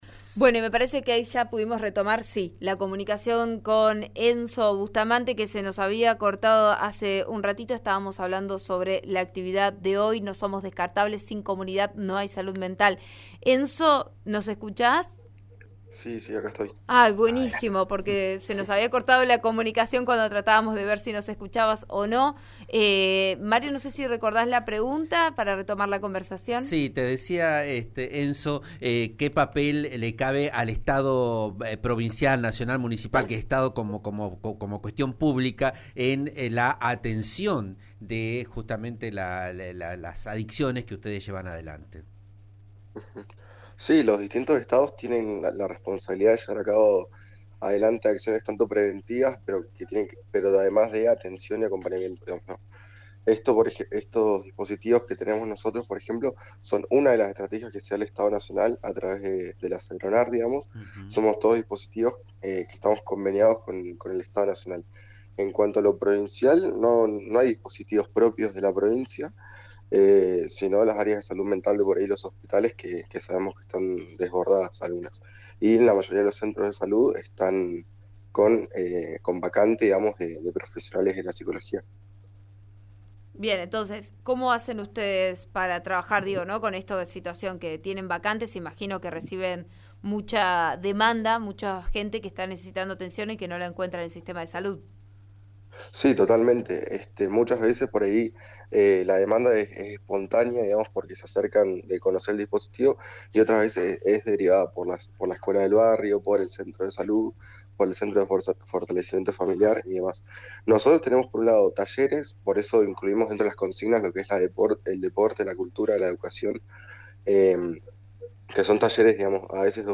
al aire de «Vos a Diario» por RN RADIO: